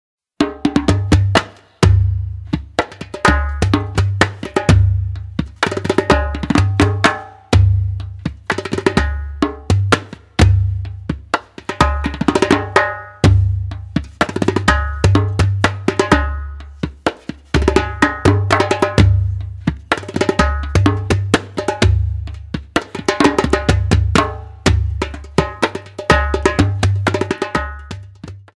Percussion and Piano Music